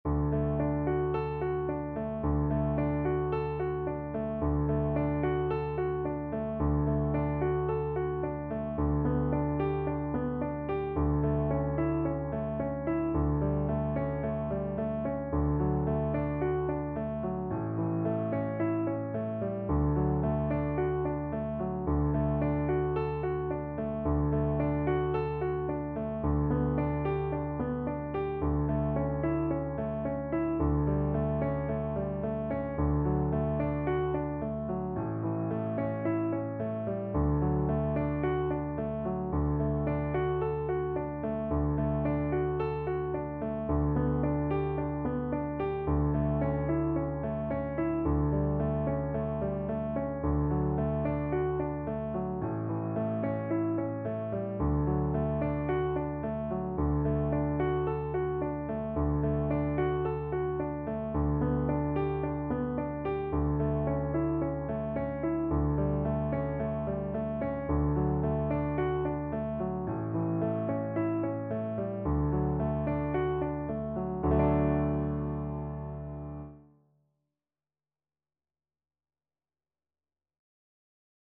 Allegro Moderato =c.110 (View more music marked Allegro)
Traditional (View more Traditional Cello Music)